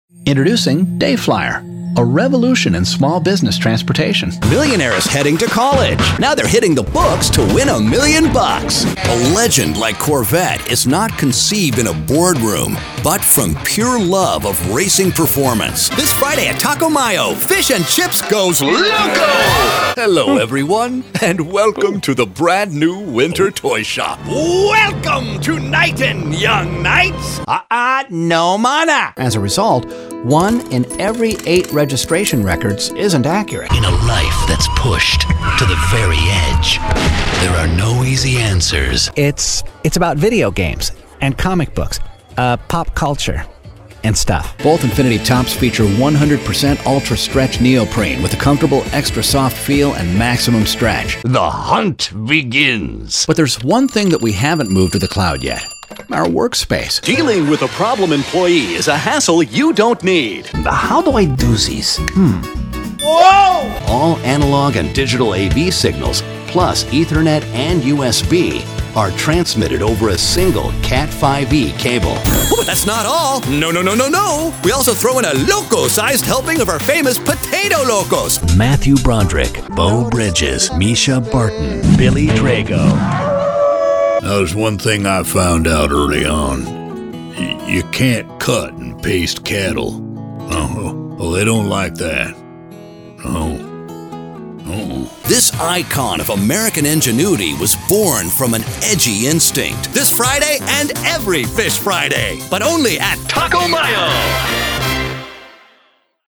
Mature Adult, Adult, Young Adult
standard us
commercial